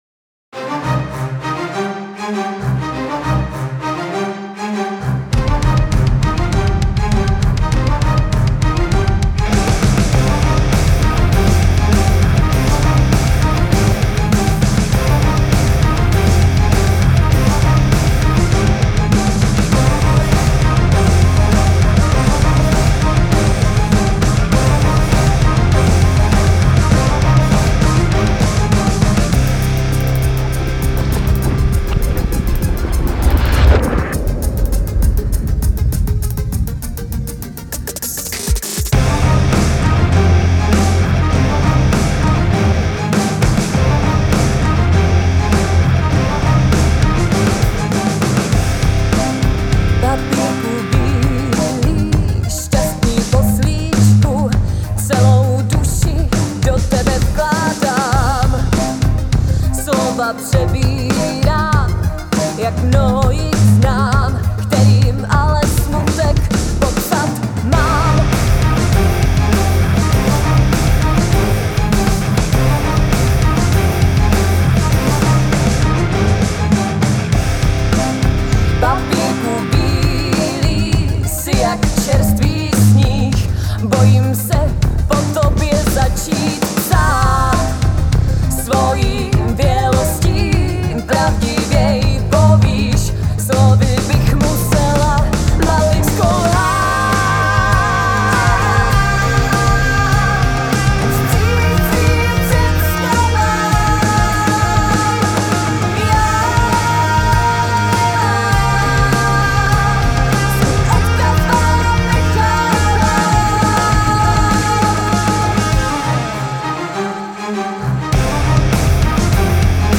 (voc)
(guit)
Předělávka písně